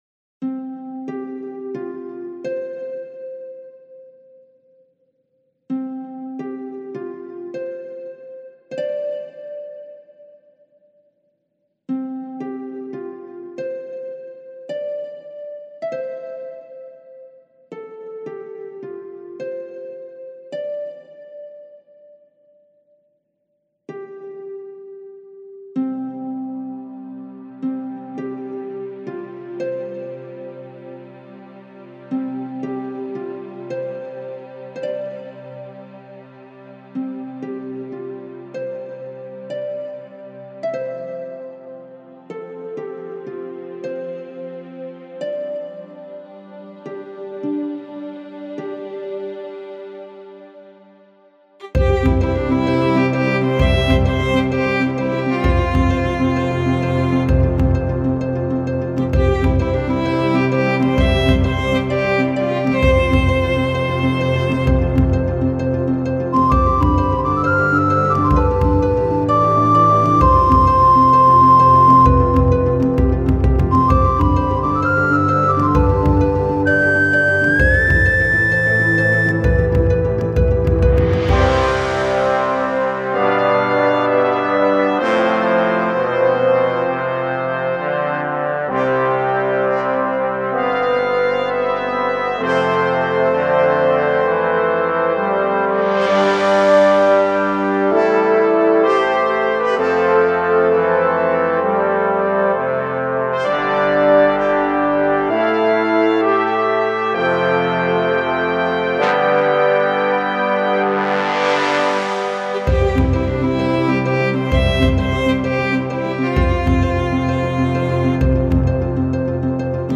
music celtic soundtrack
Sounds like epic RPG music.